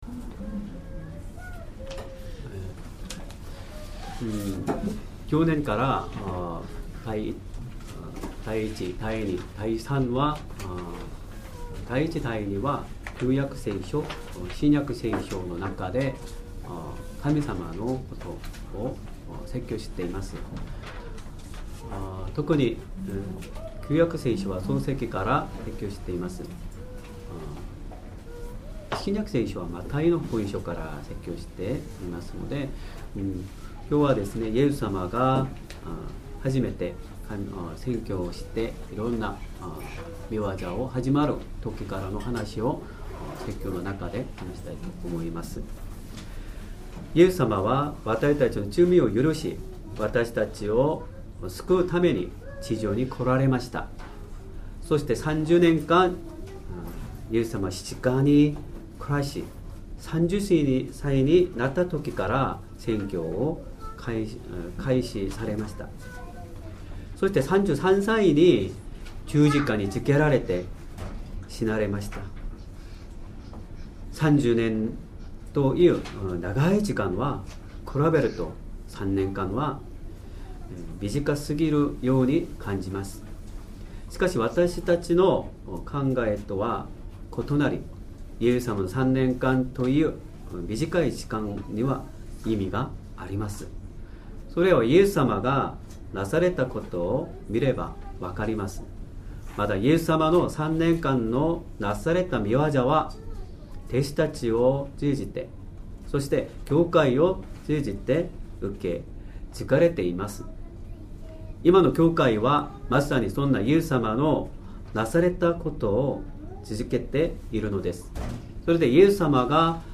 Sermon
2023年 2月4日 主日礼拝 説教 主イエス様が来られてなされた御業 マタイの福音書4章23～25節 : 4:23 イエスはガリラヤ全域を巡って会堂で教え、御国の福音を宣べ伝え、民の中のあらゆる病、あらゆるわずらいを癒やされた。